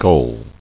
[gull]